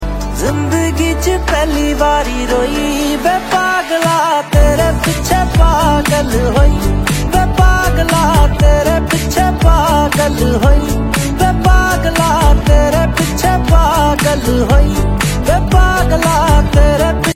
featuring the complementary backing vocals
guitars